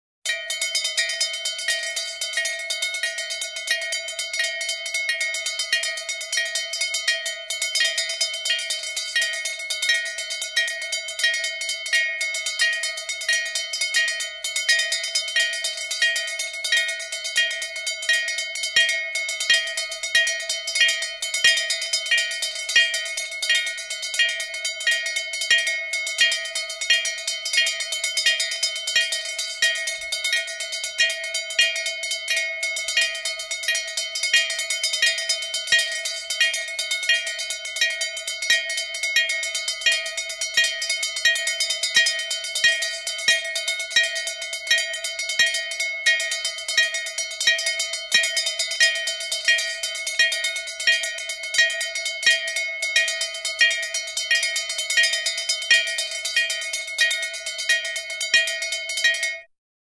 На этой странице собраны звуки железнодорожного переезда: сигнальные звонки, гудки локомотива, стук колес по рельсам.
Звук древнего железнодорожного колокола, раздавшийся на пересечении путей